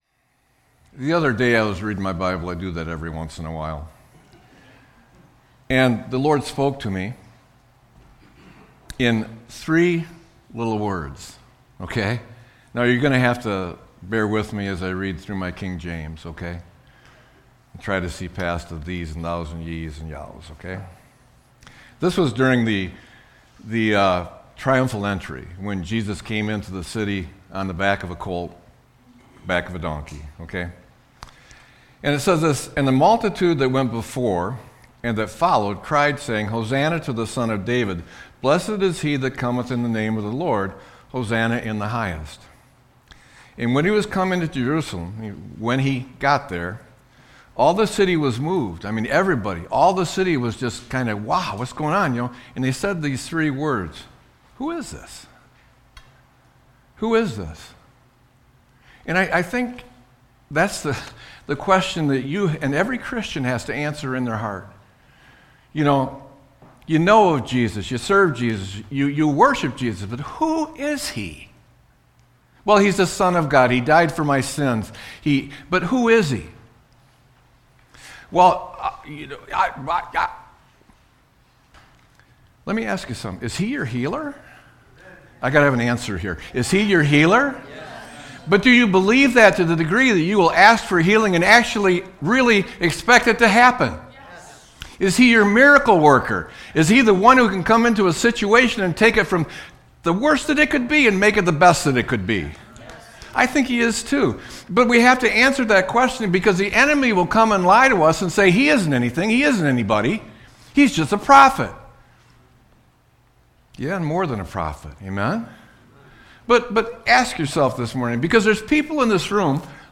Sermon-3-01-26.mp3